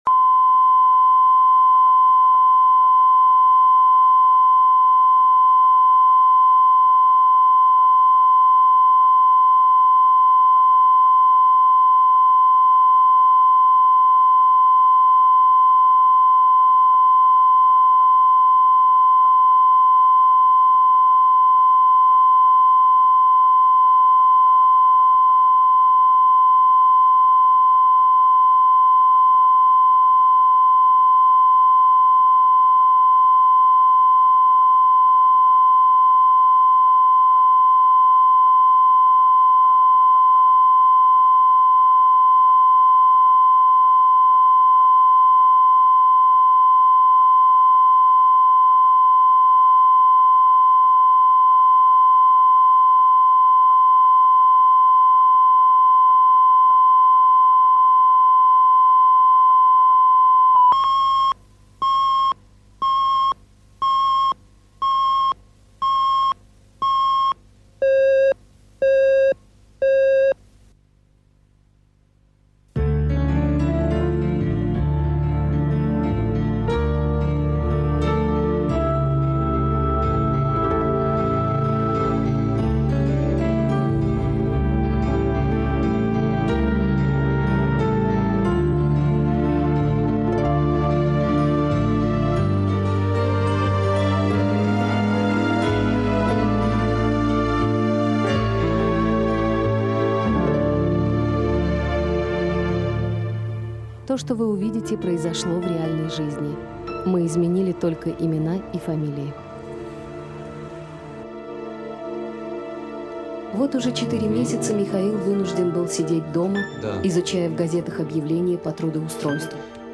Аудиокнига Букет тюльпанов